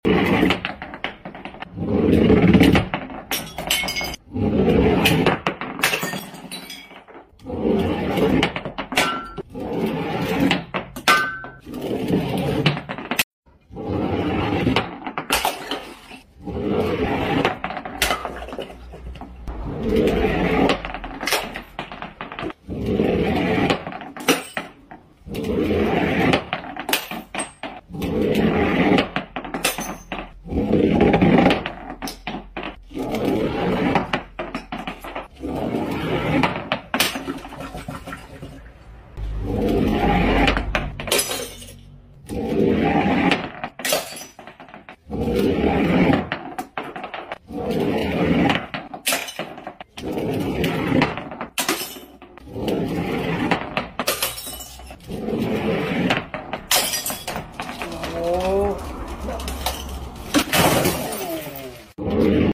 Breaking Glass Bottles AMSR sound effects free download